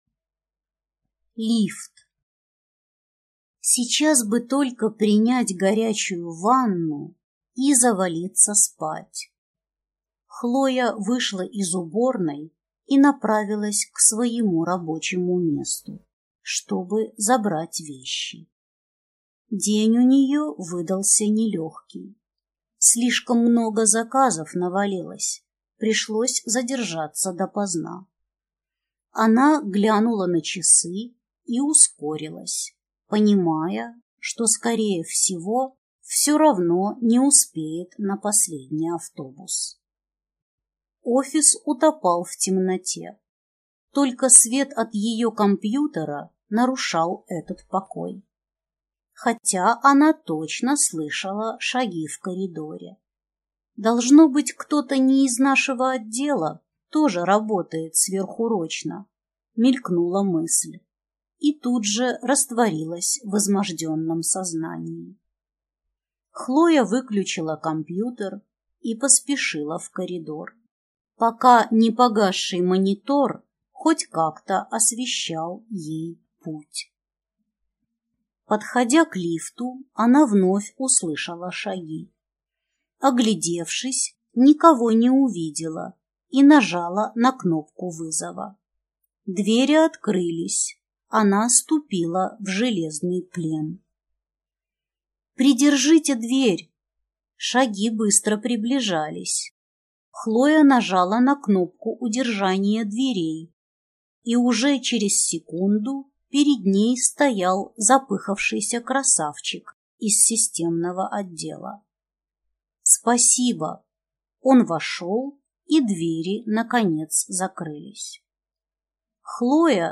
Аудиокнига Just feel it…